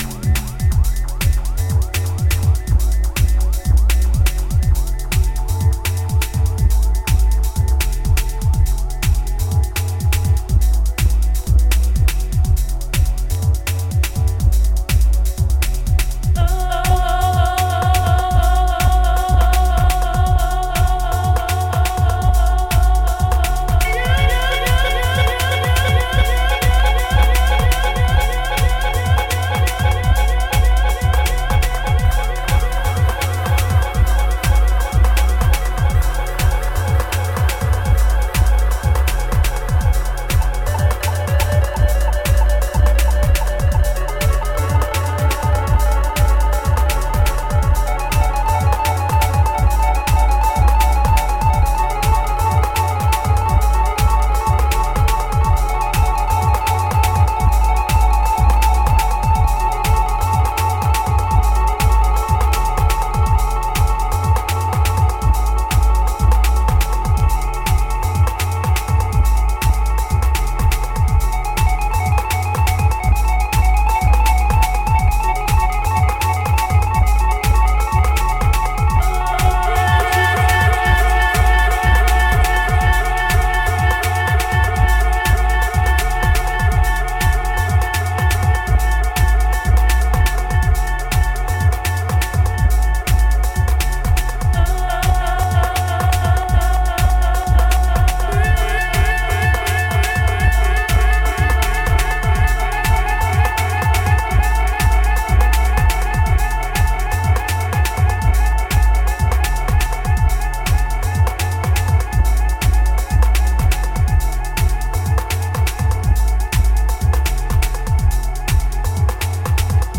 extended dub mix